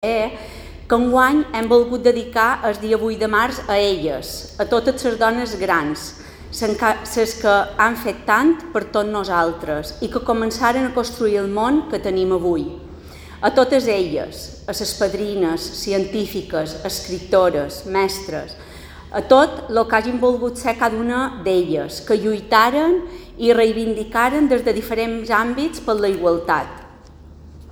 Declaraciones de la directora del IBDONA, Cati Salom